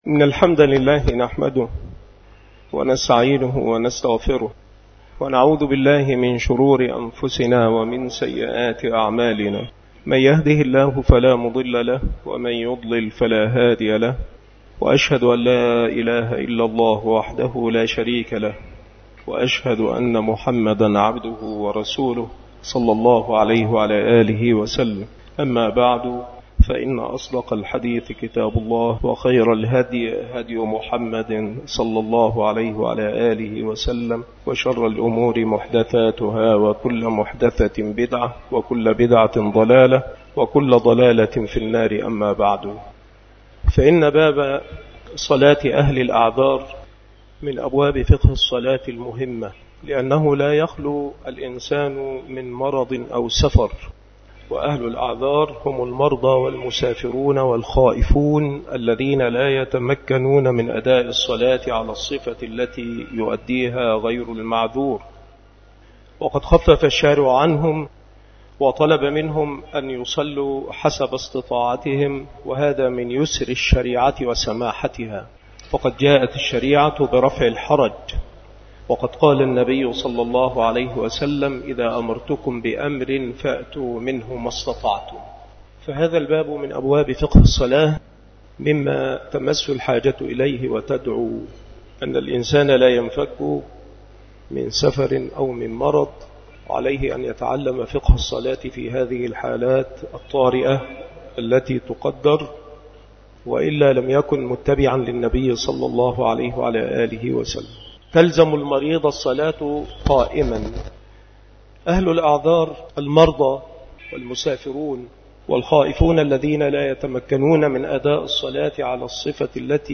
مكان إلقاء هذه المحاضرة بمسجد صلاح الدين بمدينة أشمون - محافظة المنوفية - مصر